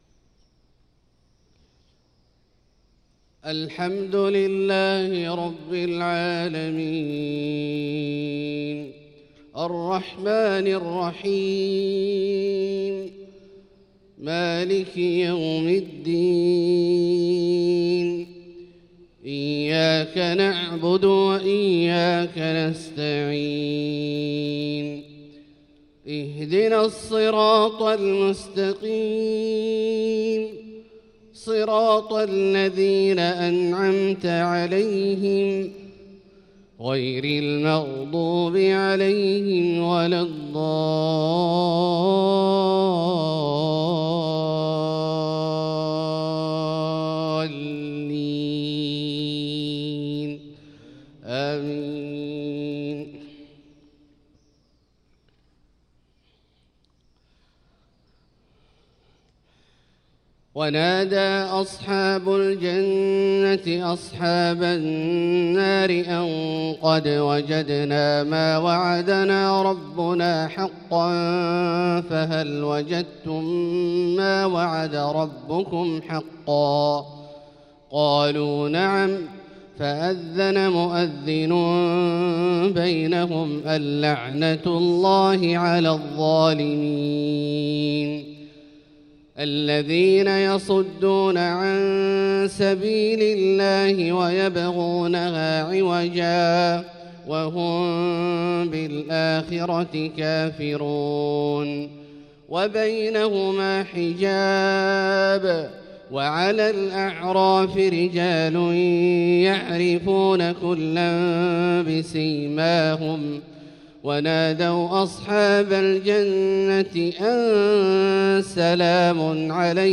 صلاة الفجر للقارئ عبدالله الجهني 15 شعبان 1445 هـ
تِلَاوَات الْحَرَمَيْن .